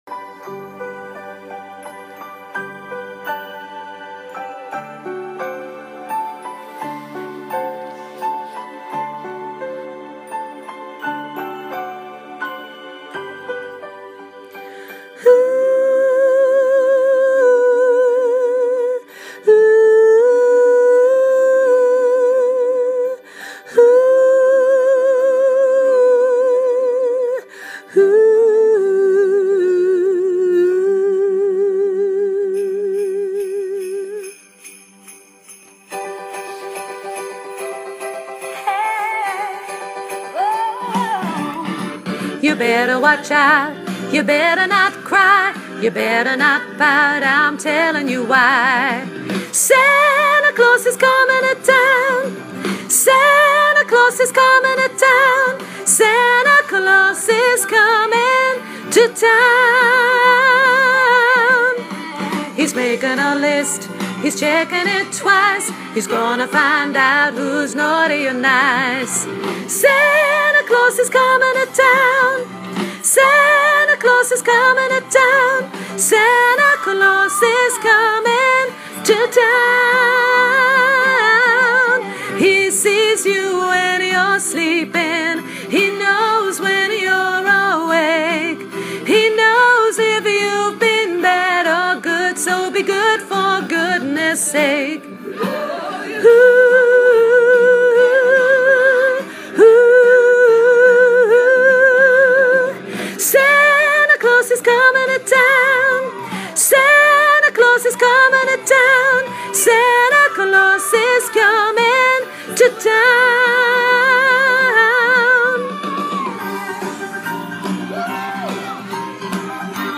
koortje sopraan